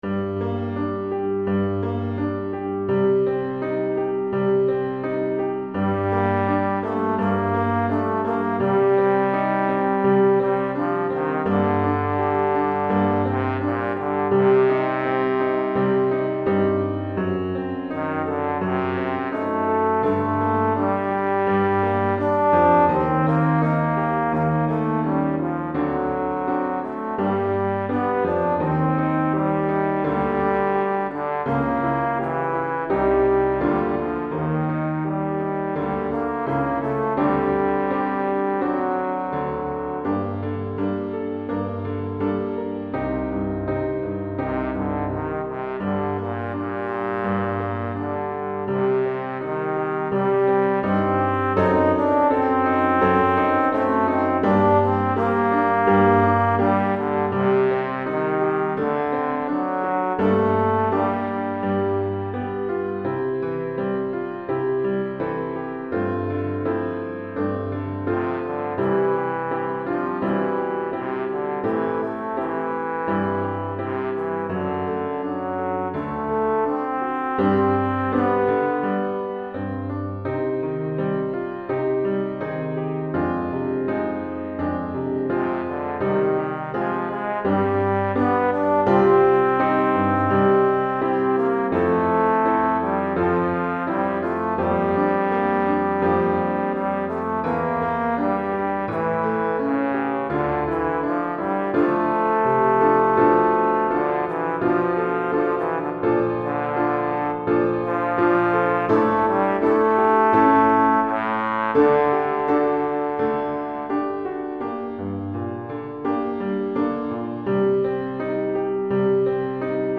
Trombone et Piano